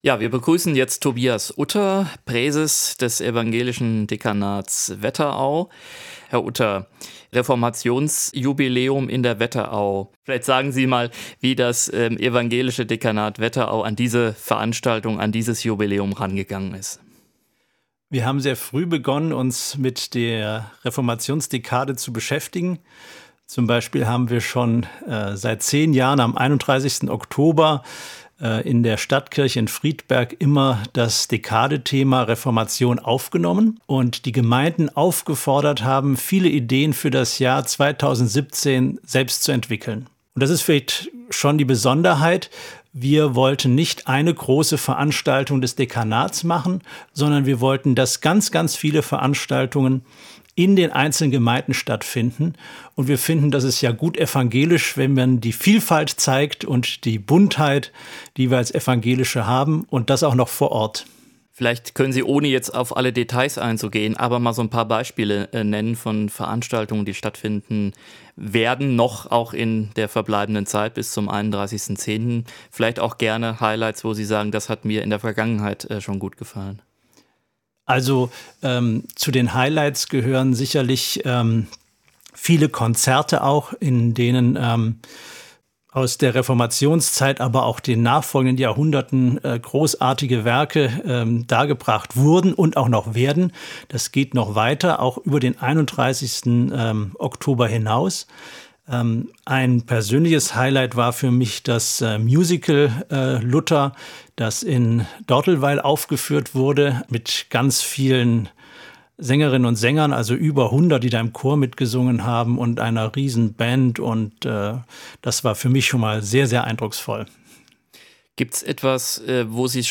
Reformationsjubiläum in der Wetterau